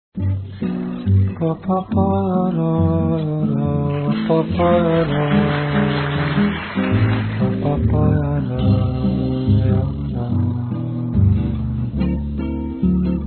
contrabbasso
batteria